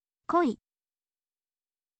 koi